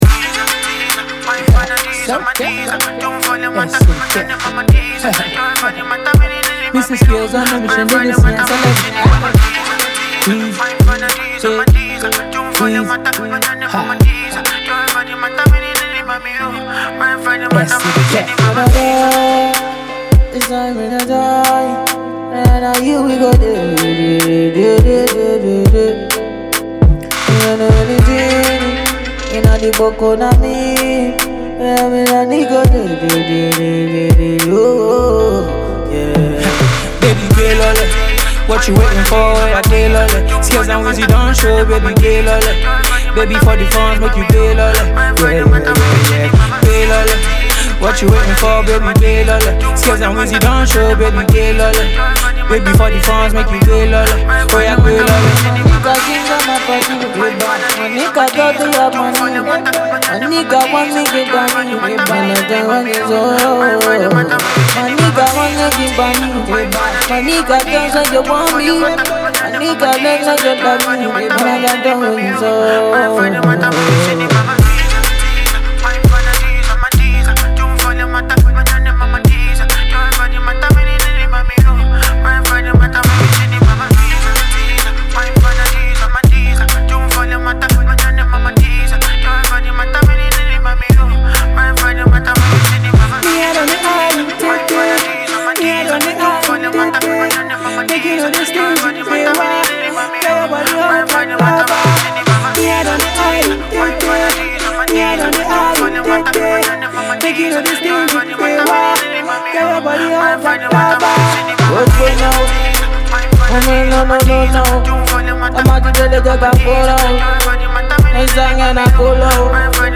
sounds like a groovy one